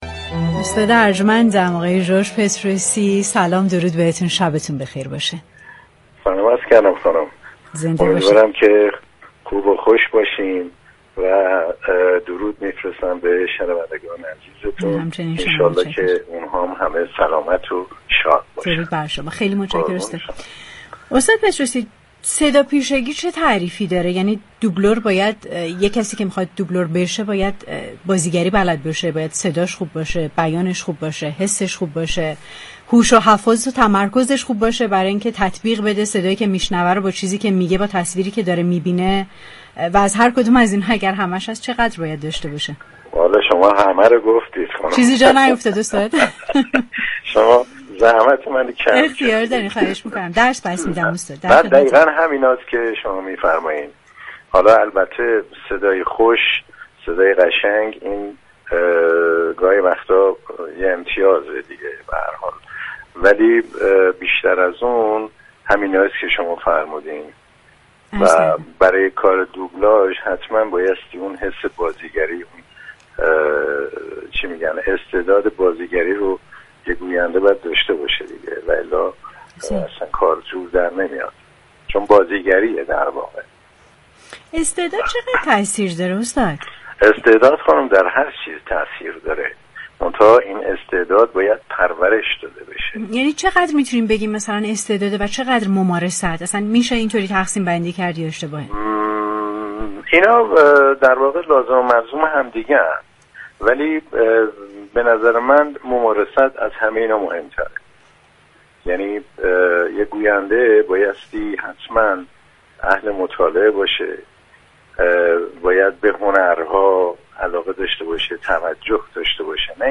دوبلور پیشكسوت كشورمان در گفتگو با برنامه پشت صحنه رادیو تهران